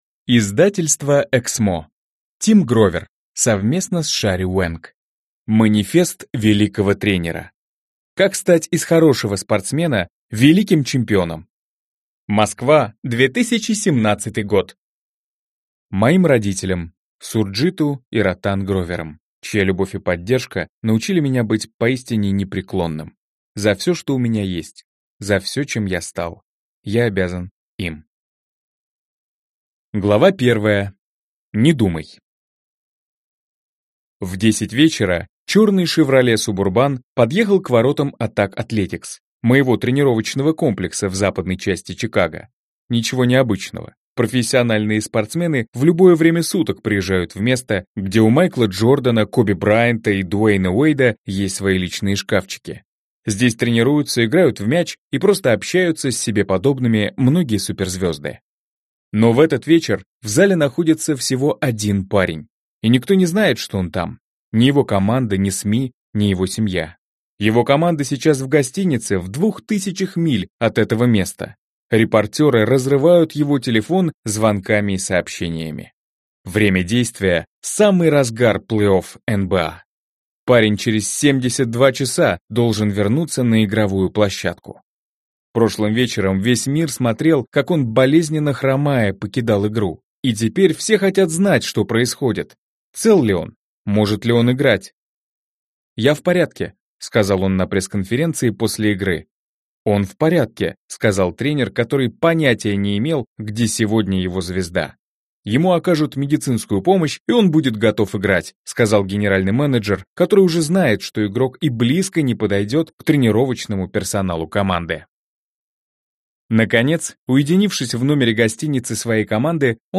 Аудиокнига Манифест великого тренера: как стать из хорошего спортсмена великим чемпионом | Библиотека аудиокниг